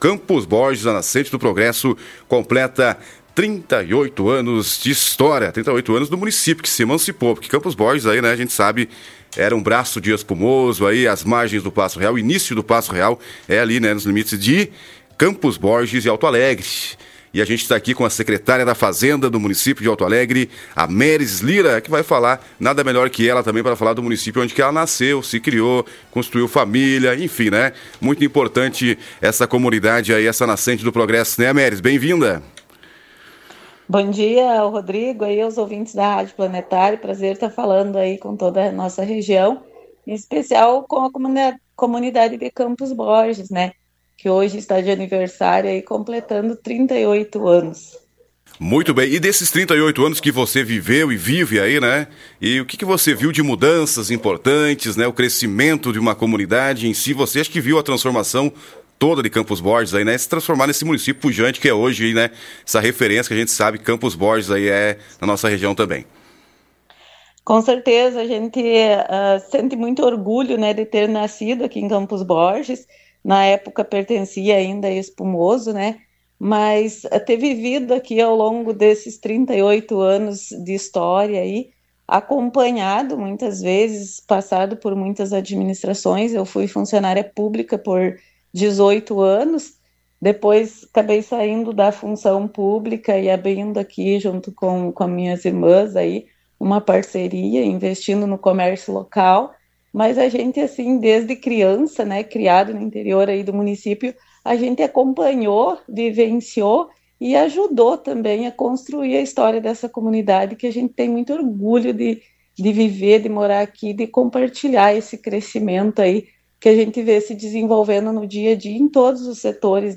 Durante entrevista à Rádio Planetária, a secretária da Fazenda do município, Ameris Lira, destacou o orgulho de acompanhar a evolução do município ao longo das décadas.